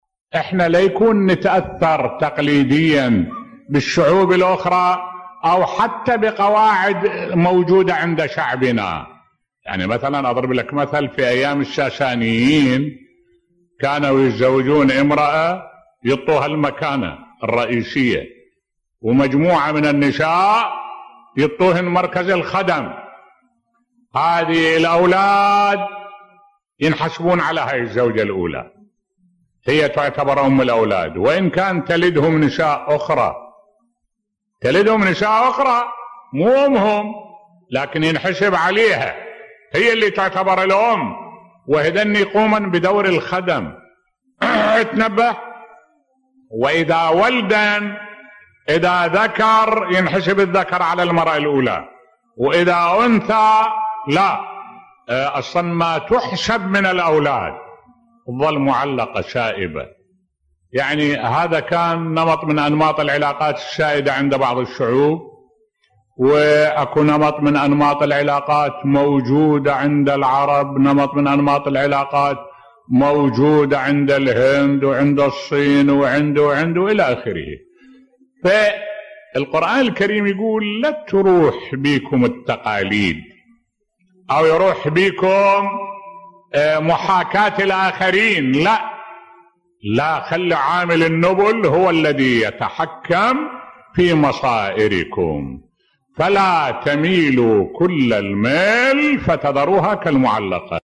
ملف صوتی الحذر من تقاليد الجاهلية بصوت الشيخ الدكتور أحمد الوائلي